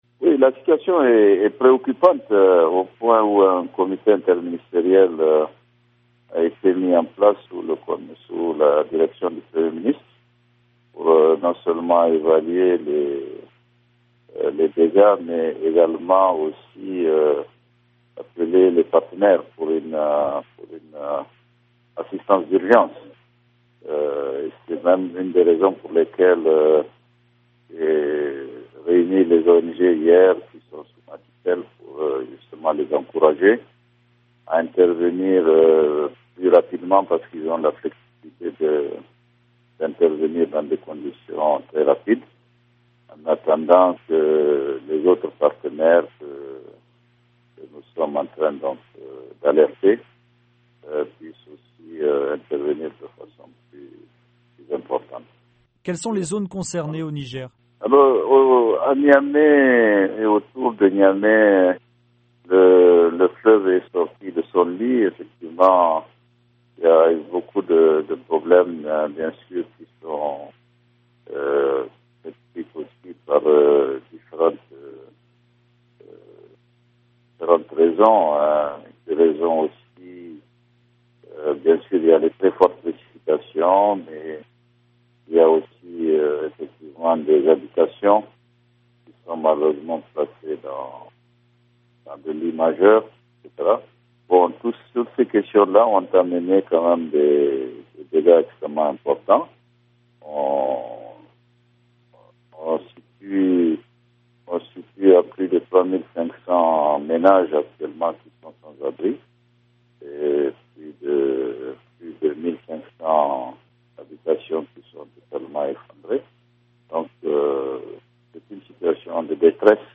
Unb entretien avec Amadou Boubacar Cissé, ministre nigérien du Développement communautaire